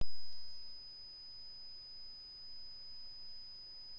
TLV320AIC3110: continuous beep on microphone
However, when I am recording, there is a continuous beep sound.
There are two analog MEMS microphones used as capture devices.
Sample record in a silence room.
The recording is working, and I can hear the recorded audio, but there is always a beep sound in the background.